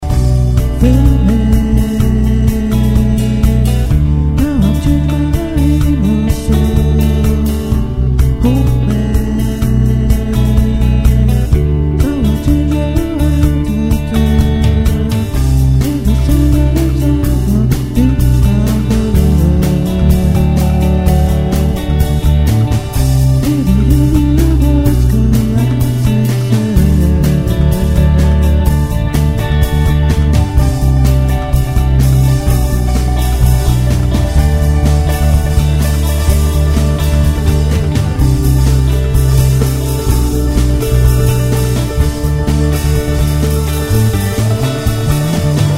The 60s and 70s R & B re-visited